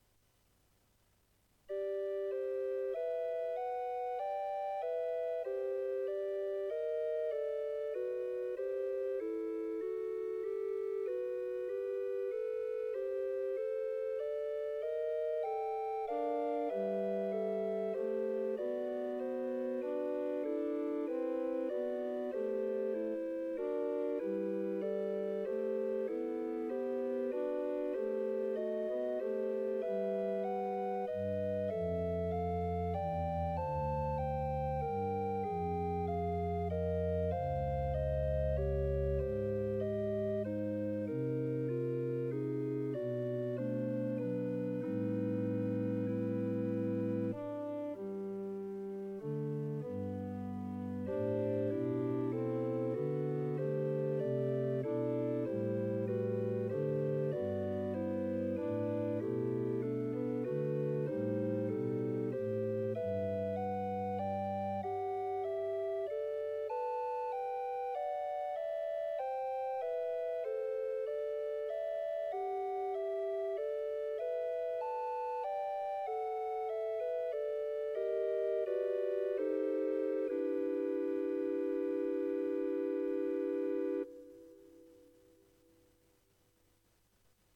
Préludes à 2claviers pedaliter